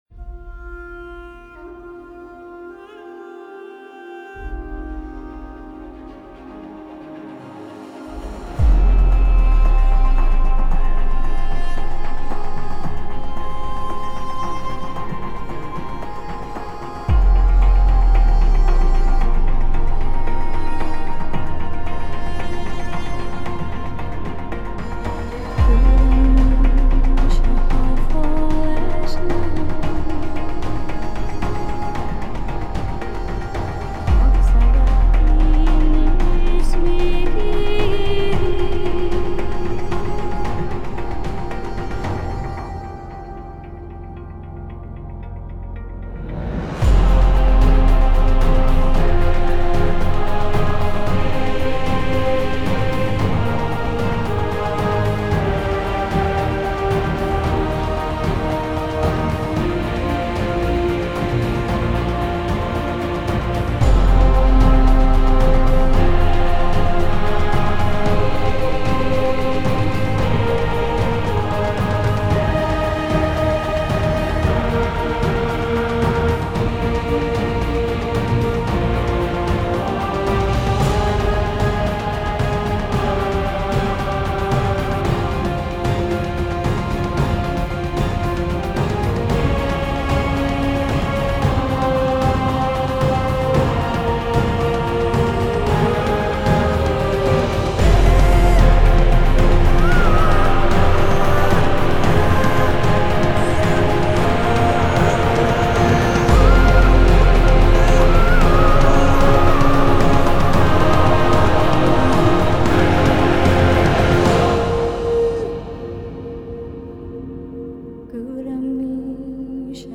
Colonna sonora del menu' principale